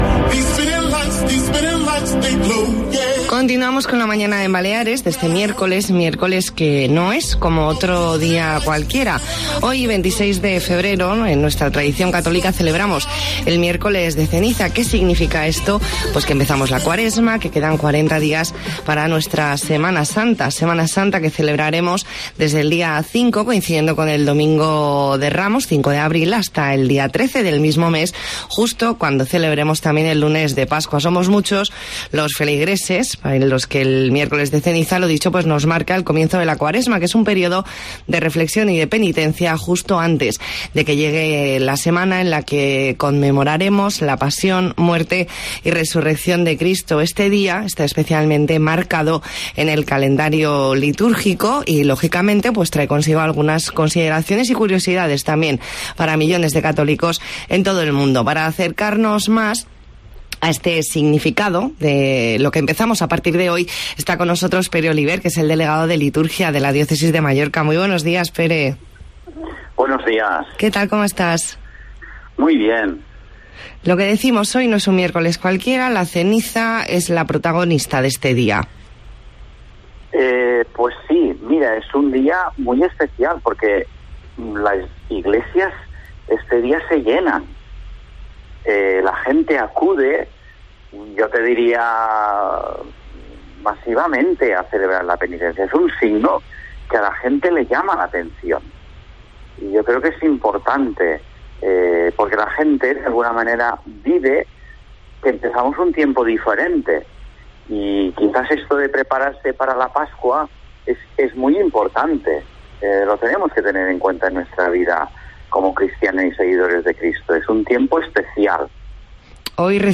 Entrevista en La Mañana en COPE Más Mallorca, miércoles 26 de febrero de 2020.